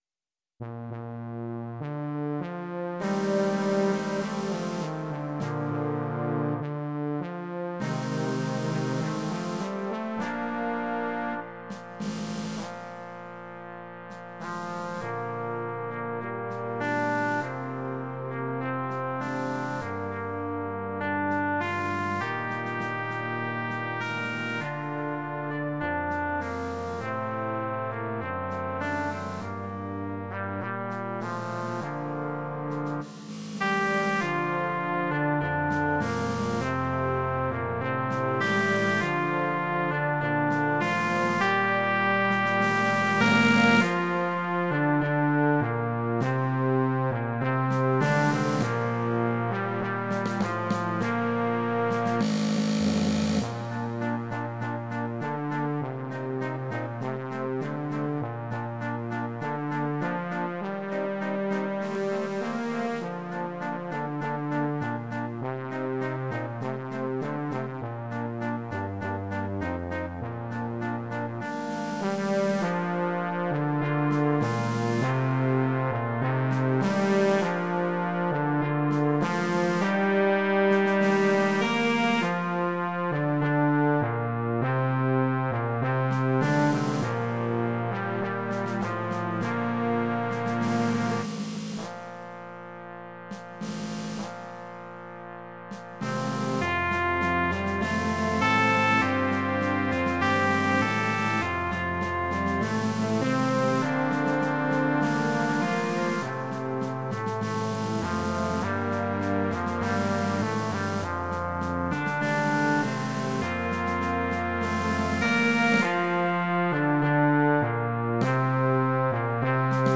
Music for brass ensemble.